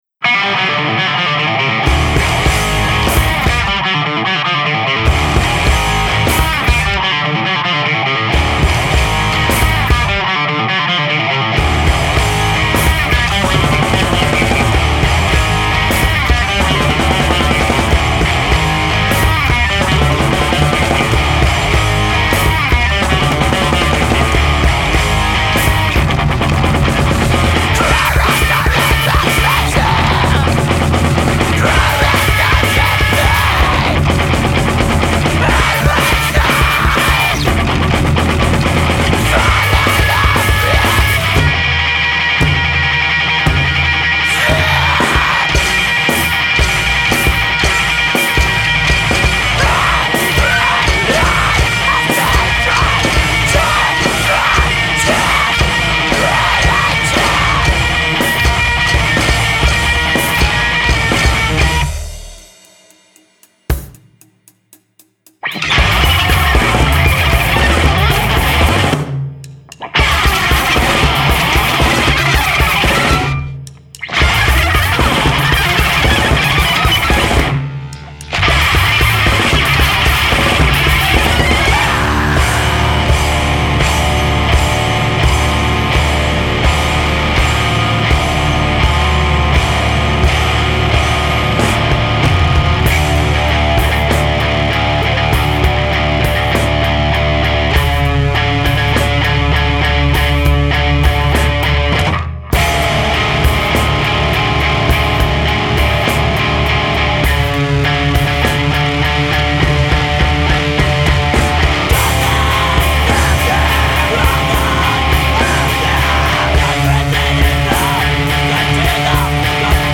screamo et post hardcore